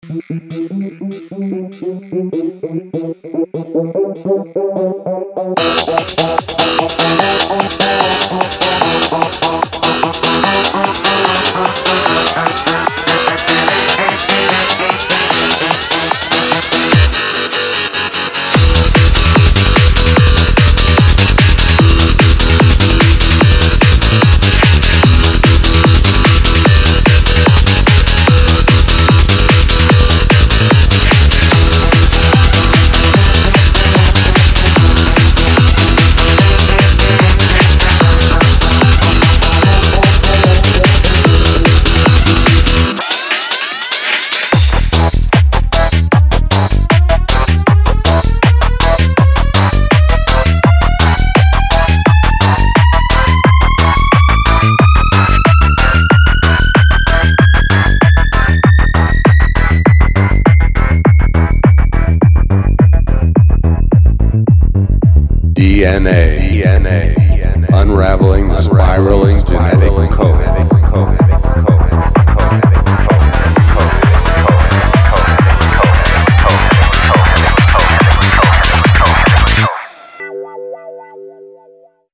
• Качество: 290, Stereo
психо
Trans
психоделический транс.